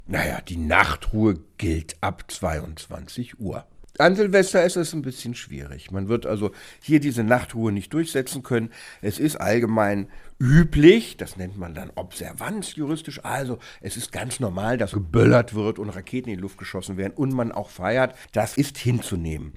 O-Ton Silvester1: Wie viel Lärm ist erlaubt?